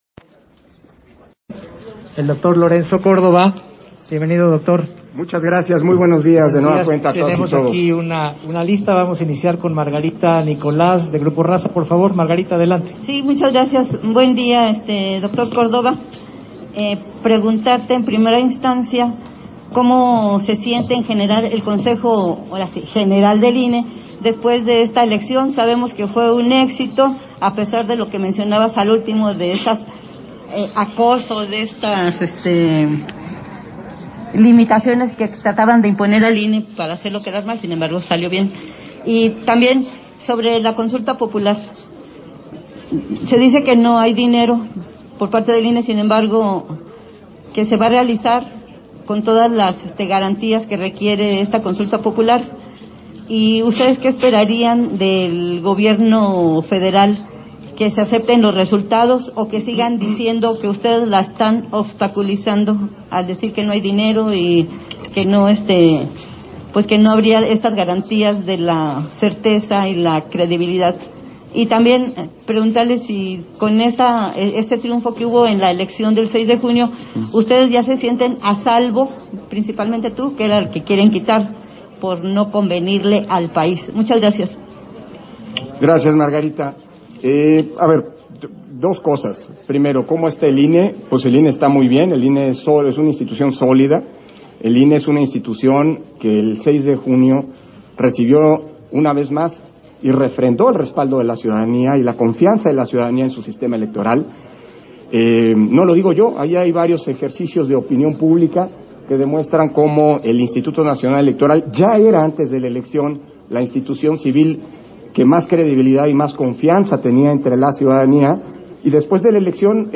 280621_AUDIO_CONFERENCIA-DE-PRENSA